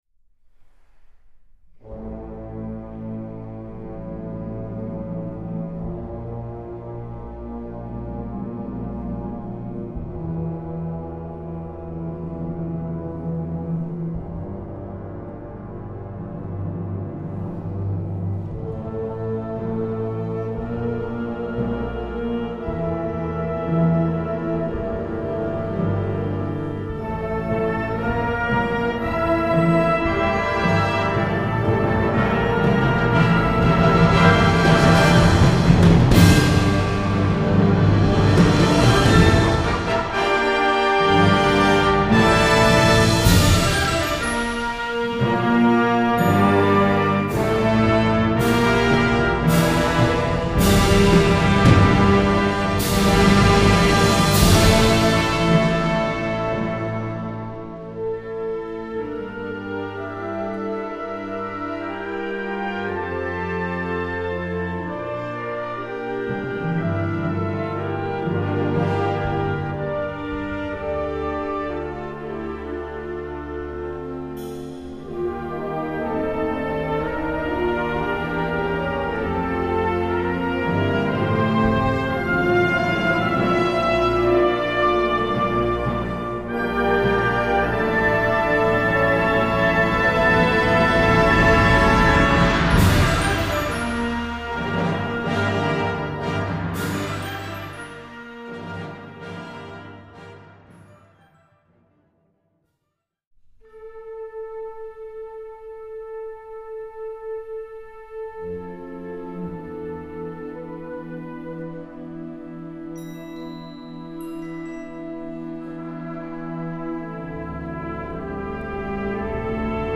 Catégorie Harmonie/Fanfare/Brass-band
Sous-catégorie Suite
Instrumentation Ha (orchestre d'harmonie)
1. Fanfare
2. Chorale
3. March
pour orchestre à vent